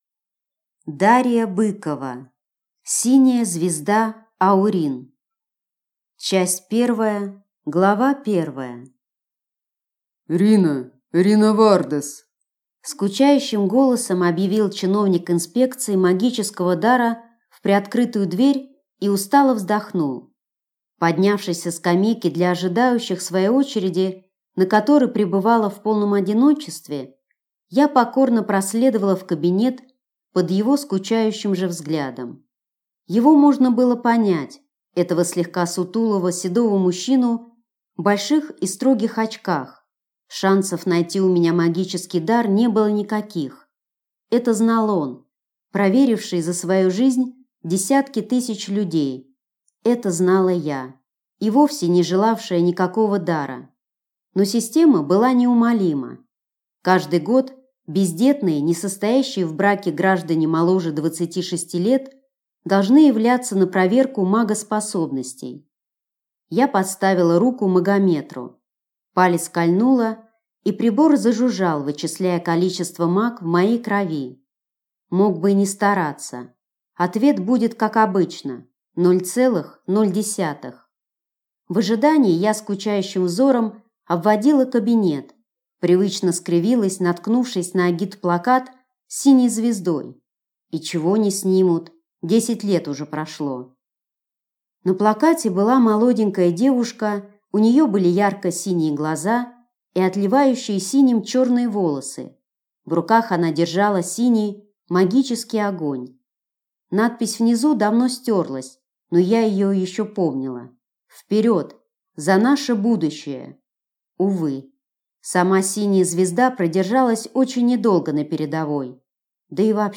Аудиокнига Синяя звезда Аурин | Библиотека аудиокниг
Прослушать и бесплатно скачать фрагмент аудиокниги